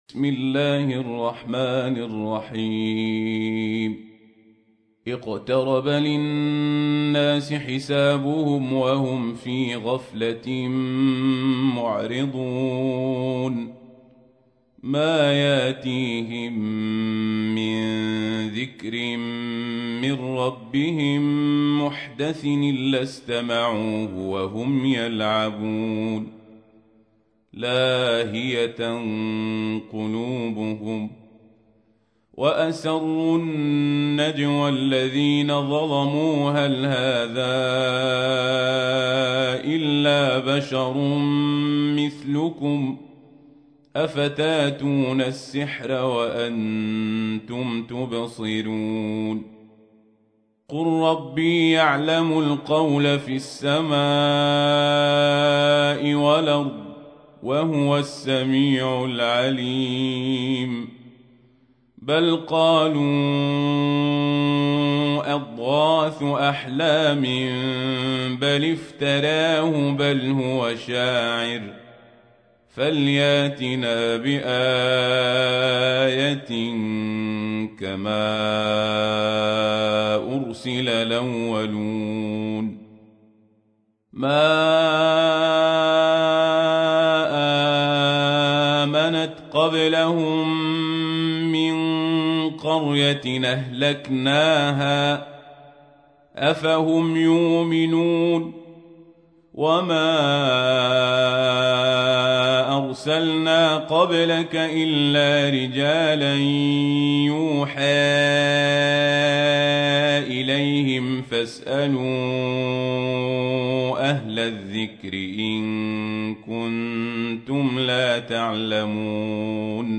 تحميل : 21. سورة الأنبياء / القارئ القزابري / القرآن الكريم / موقع يا حسين